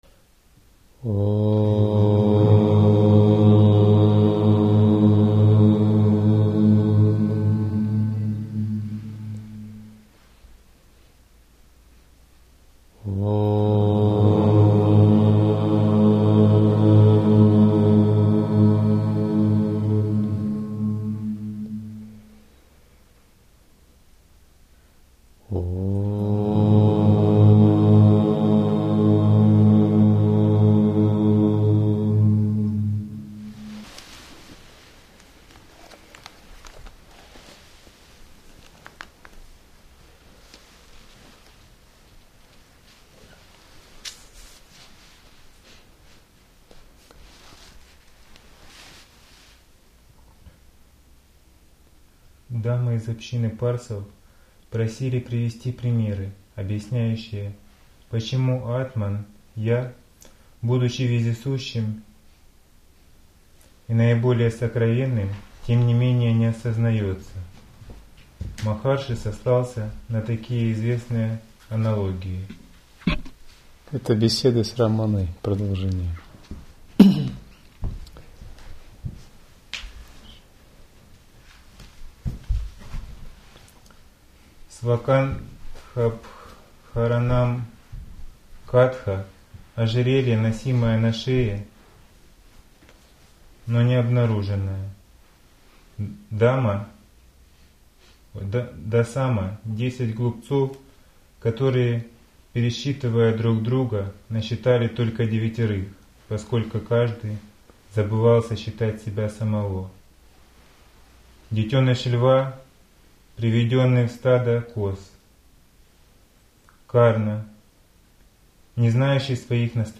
Лекции